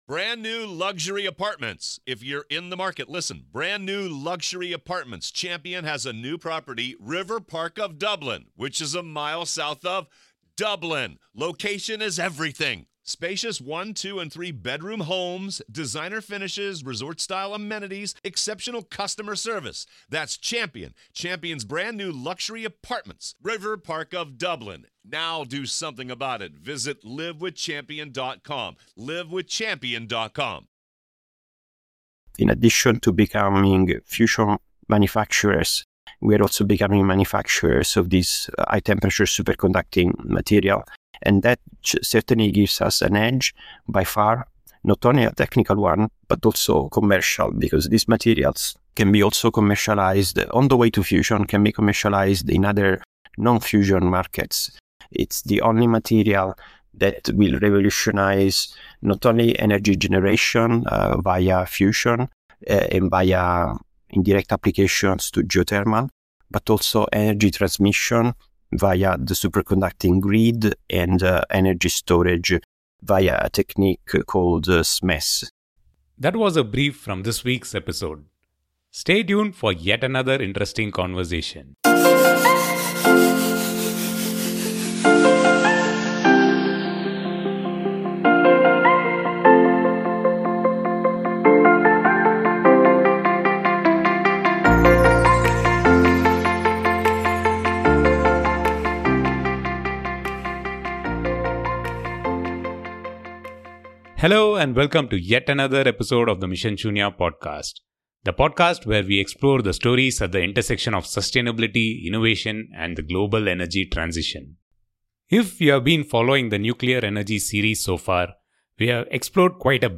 A bold, science-driven conversation on the future of clean energy.